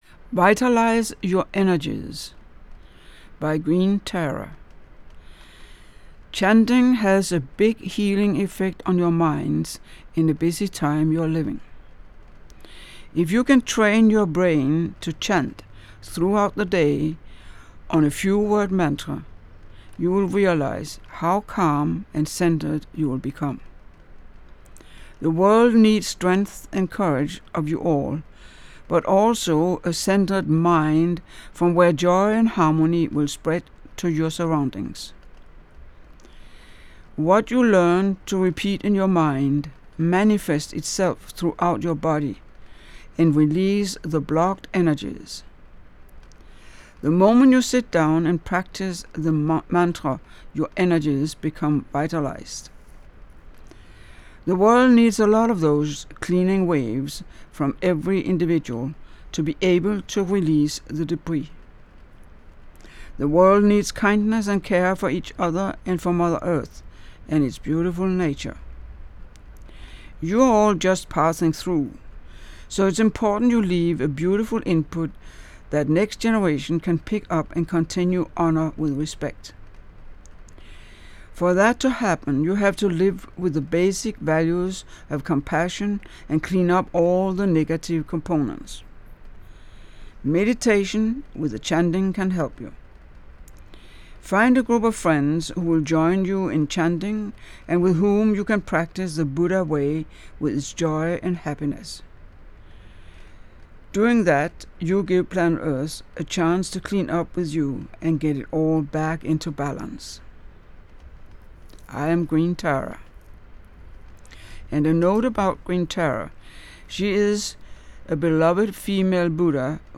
Meditation with a chanting can help you.